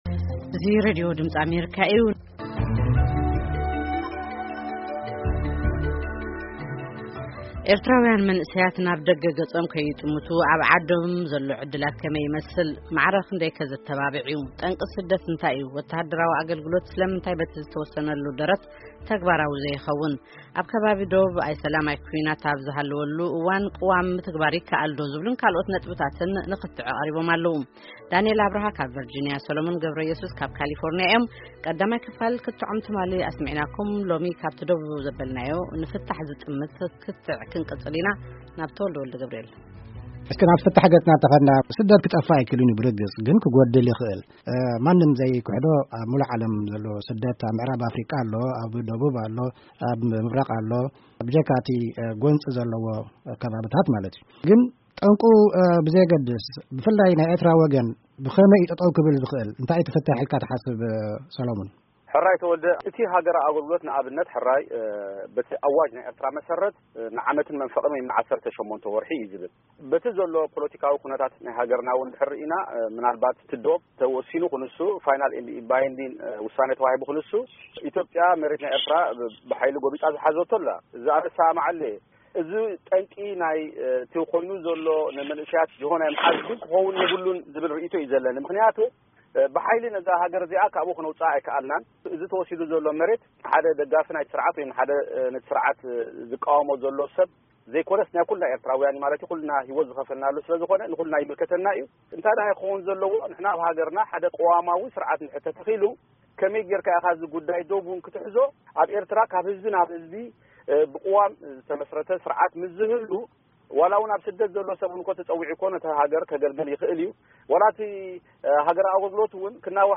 ኽትዕ፡ ክልተ ኤርትራውያን ኣብ ጠንቂ ስደት መንእሰያት(2ይ ክፋል)